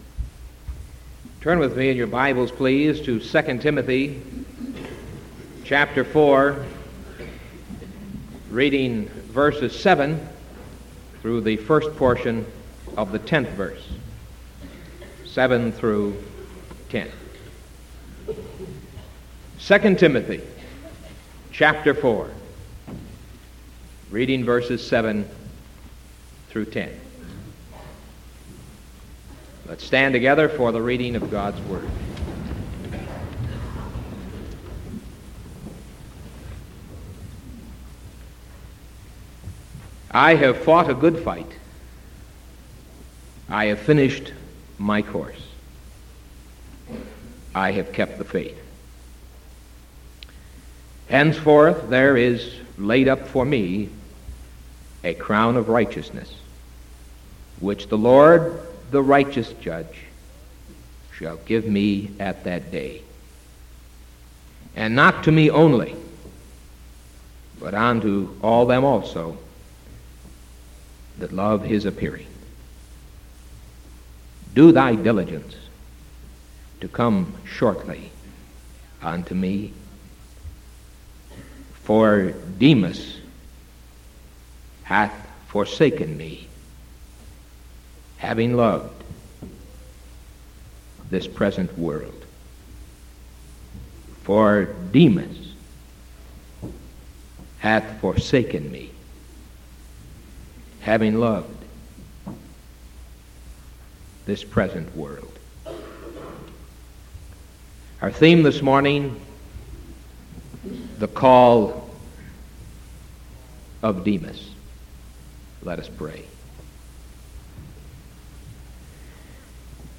Sermon January 27th 1974 AM